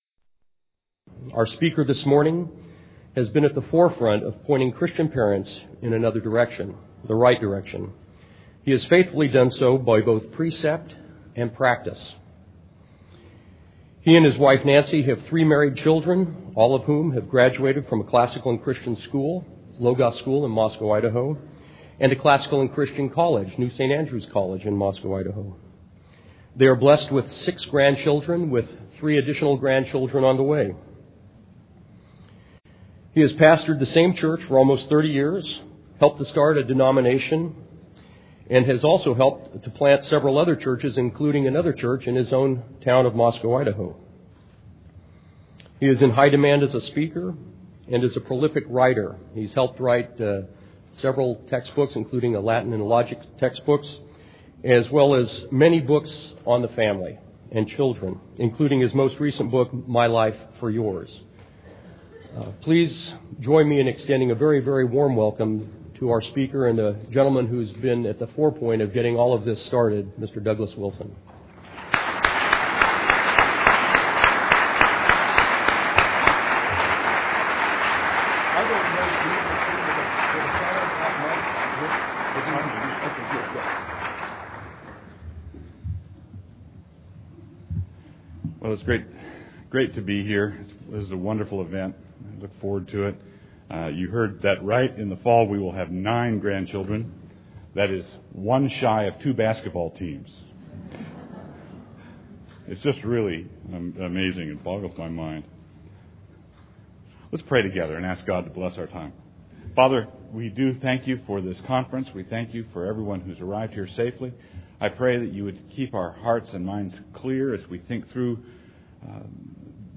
2004 Plenary Talk | 1:00:05 | Culture & Faith, Virtue, Character, Discipline